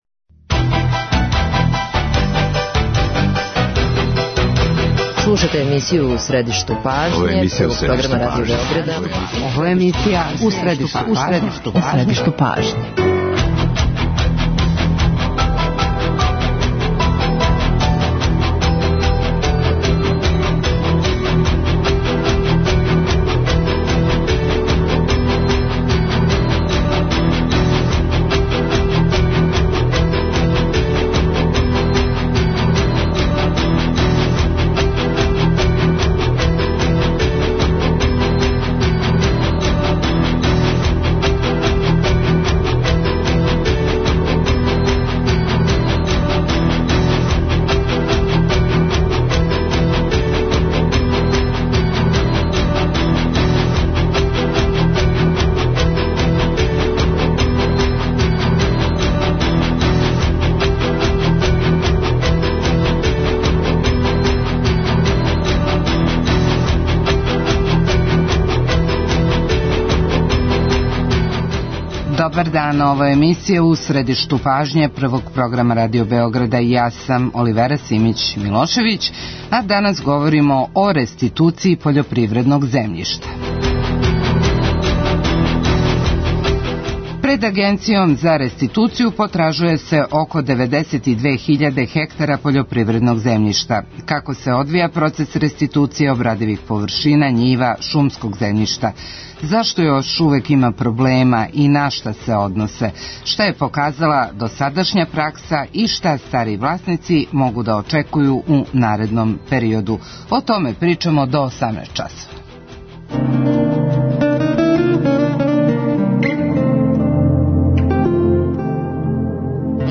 интервју